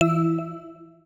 UIClick_Clean Tonal Button 01.wav